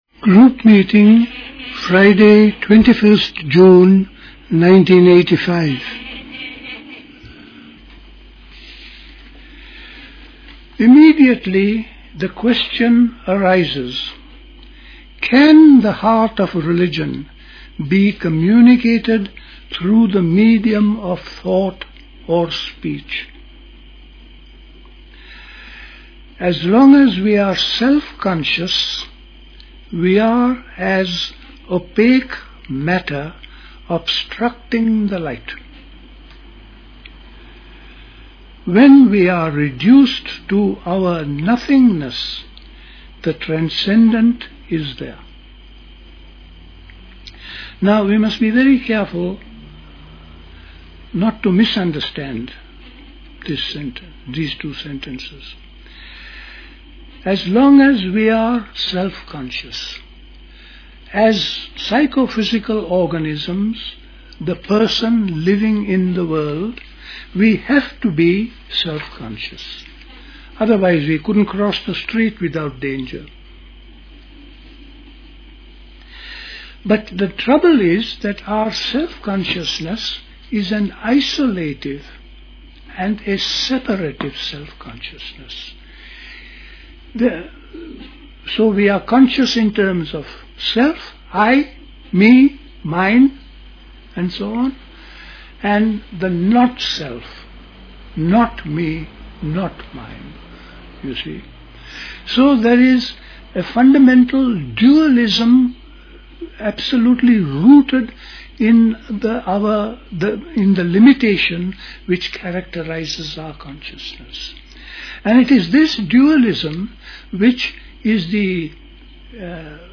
at Dilkusha, Forest Hill, London on 21st June 1985